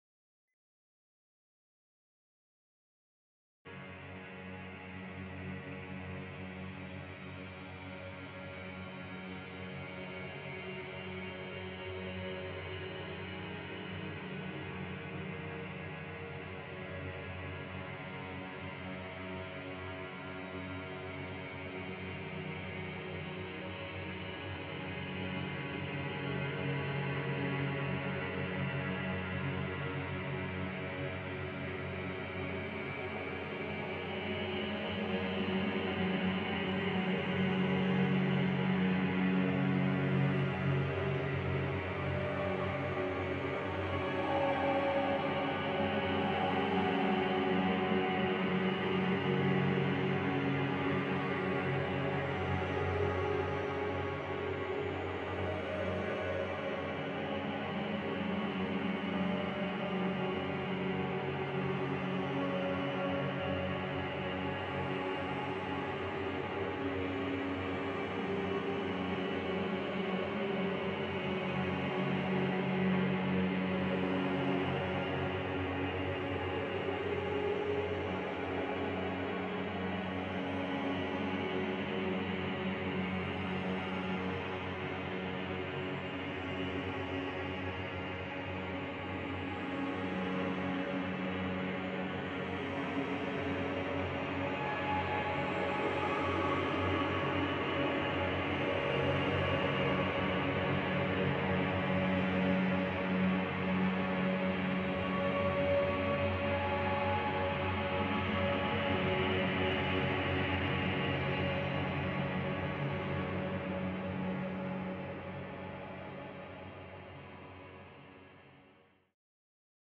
ambient-mood.mp3